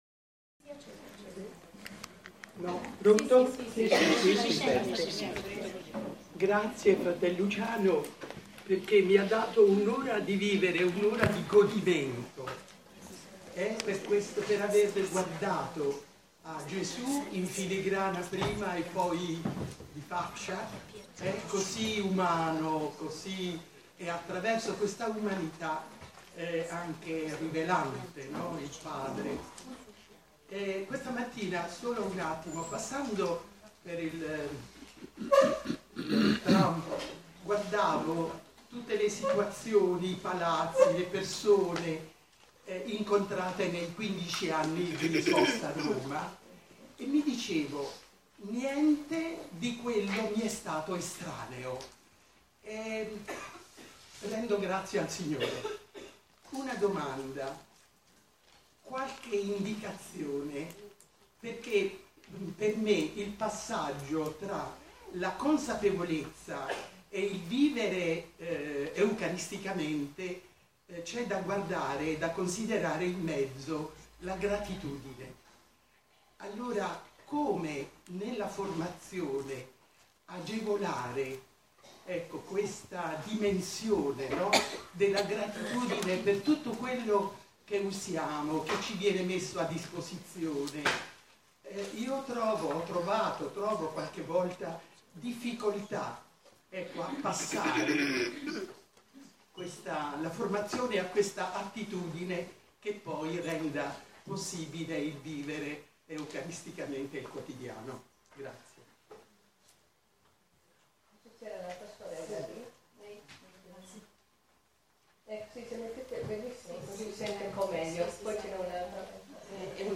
Nei giorni 10-12 novembre c.a., presso la sede USMI di Via Zanardelli, si è svolto il Convegno nazionale per superiore maggiori e consigli.